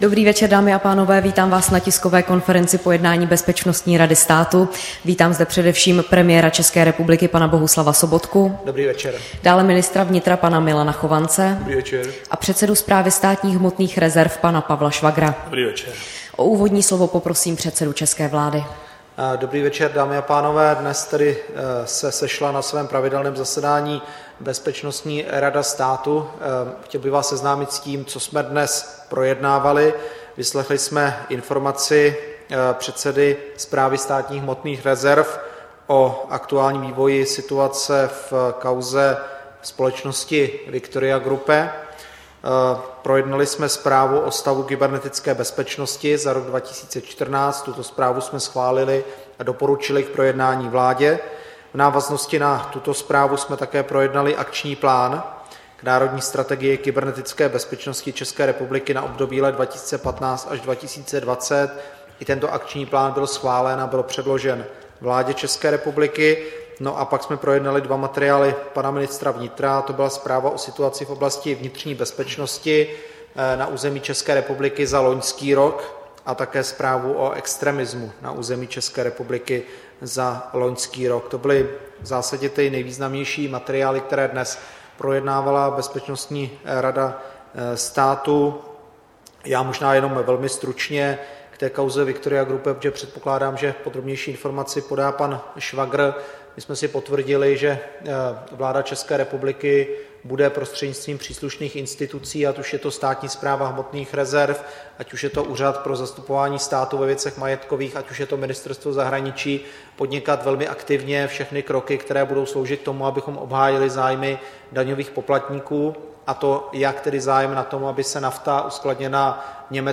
Tisková konference po jednání Bezpečnostní rady státu, 22. dubna 2015